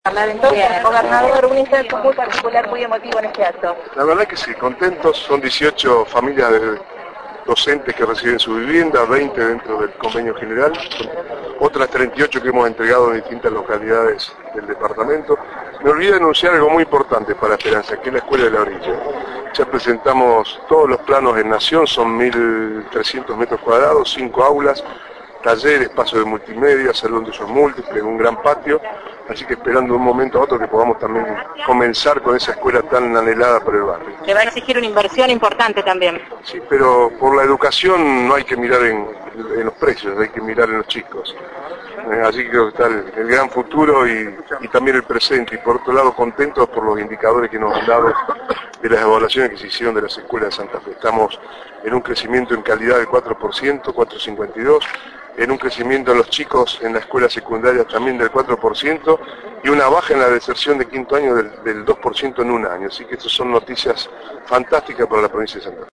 Declaraciones de Bonfatti: “Vamos a construir una nueva escuela en Esperanza, en el barrio La Orilla